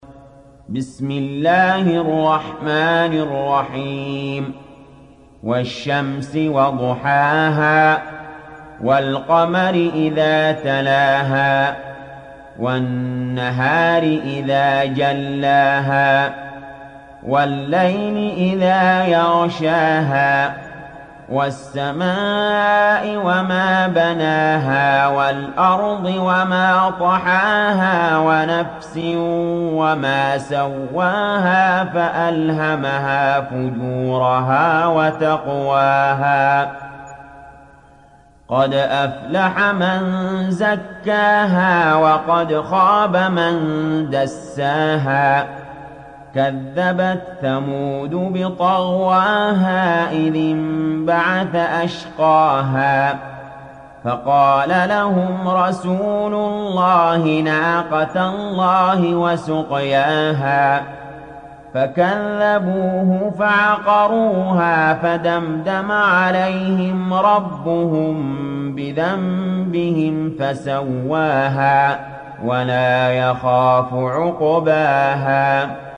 تحميل سورة الشمس mp3 بصوت علي جابر برواية حفص عن عاصم, تحميل استماع القرآن الكريم على الجوال mp3 كاملا بروابط مباشرة وسريعة